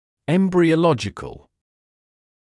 [ˌembrɪə»lɔʤɪkl][ˌэмбриэ’лодиджикл]эмбриологический